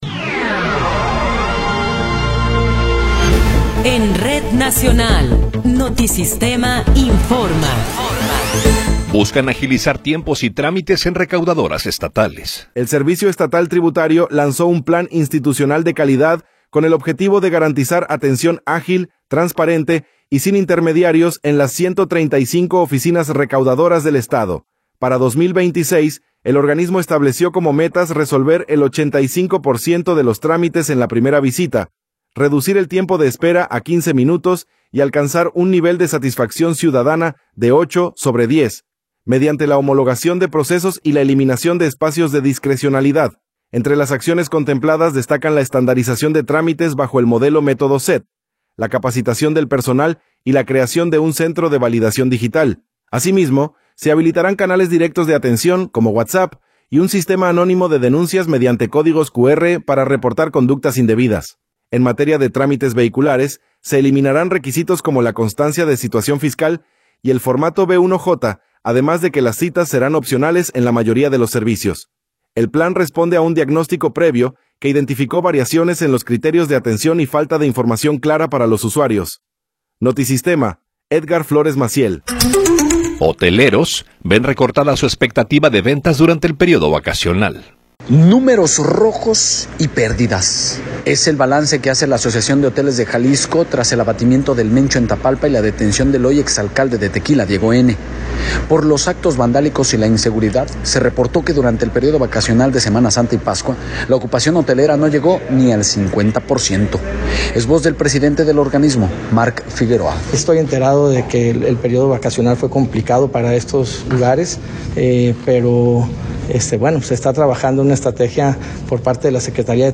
Noticiero 16 hrs. – 21 de Abril de 2026
Resumen informativo Notisistema, la mejor y más completa información cada hora en la hora.